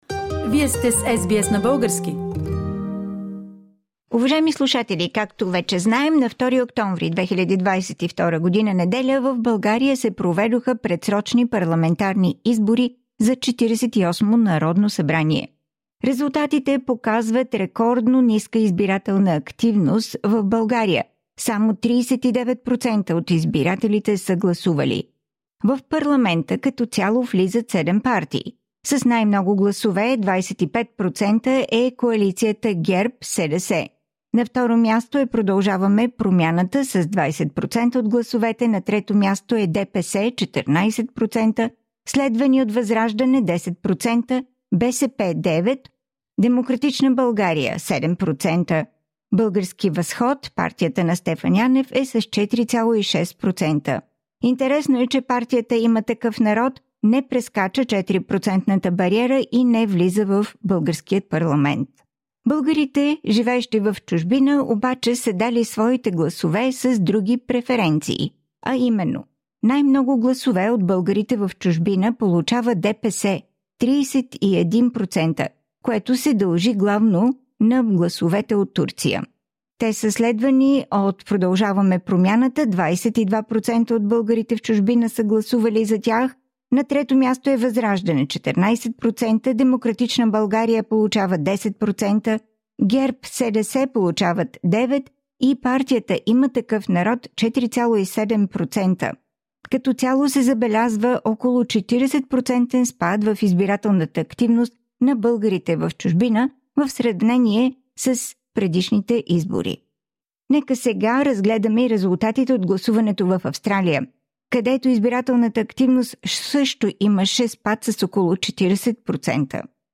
Предлагам ви да чуете кратък репортаж от изборния ден в Мелбърн на 2 октомври 2022г.